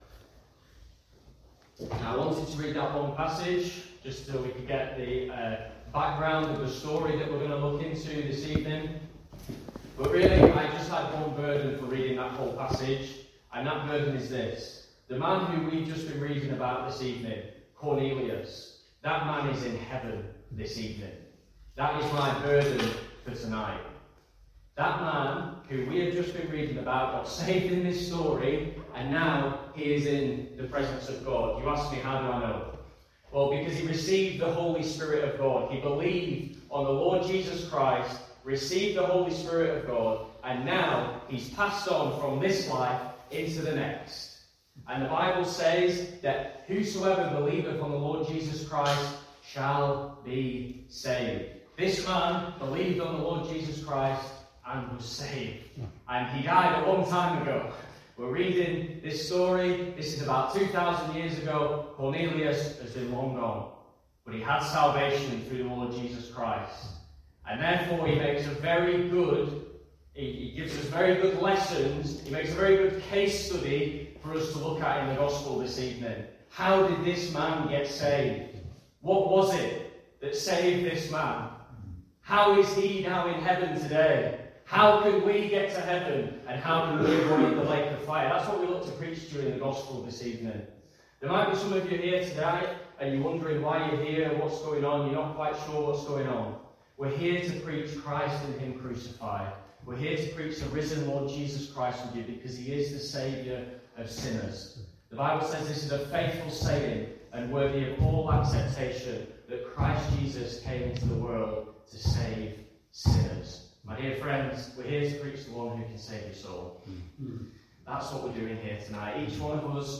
preaches the conversion of Cornelius which, like any other conversion, was by repentance toward God and faith in our Lord Jesus Christ. The truth of the gospel message is that God desires that we should all be ‘repent and be converted’. Repentance is the necessary change of heart toward sin that God expects of us.